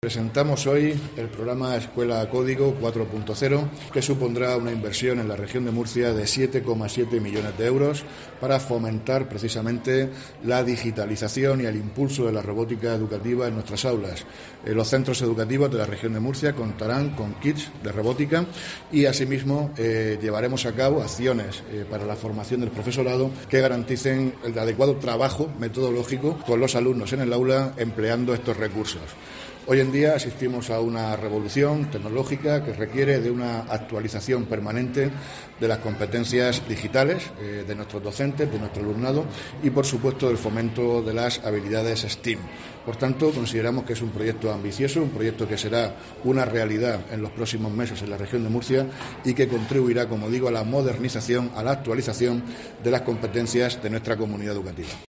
Víctor Marín, consejero de Educación, Formación Profesional y Empleo